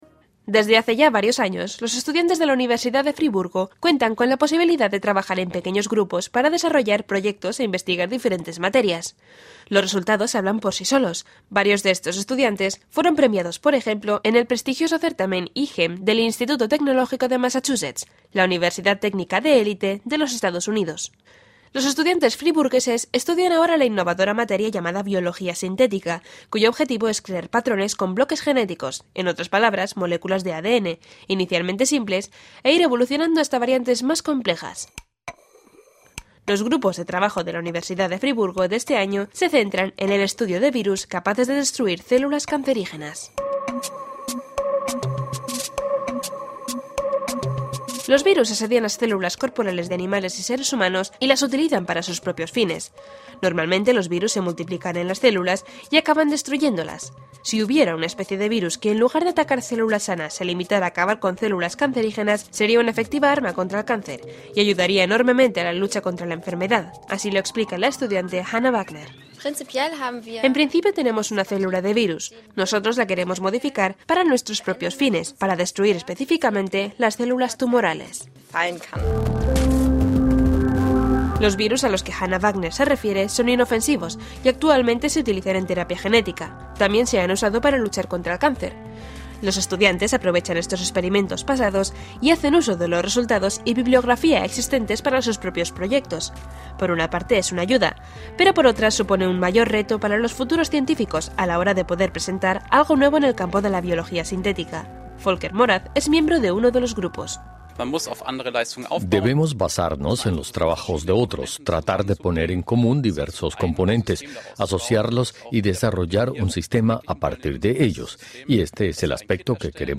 Estudiantes de la universidad de Friburgo trabajan en lo que se denomina virus devoradores de cáncer. Escuche el informe de la radio Deutsche Welle.